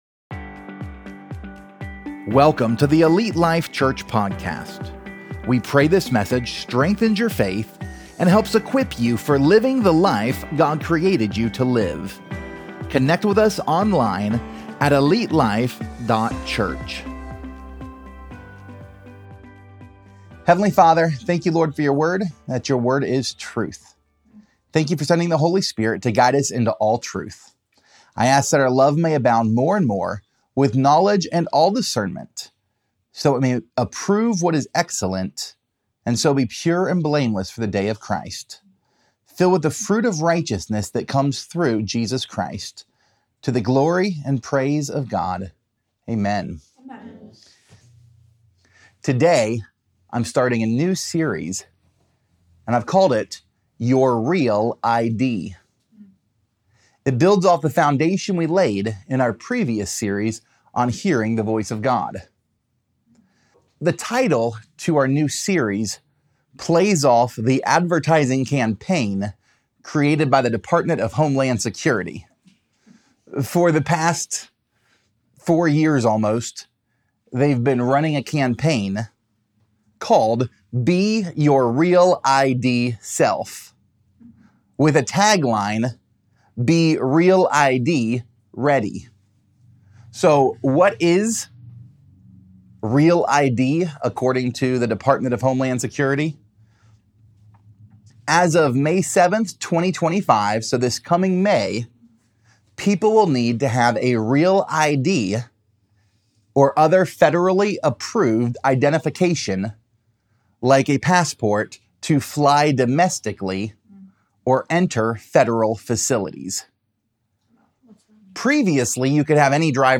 Pt 01: What Is Truth? | Your REAL ID Sermon Series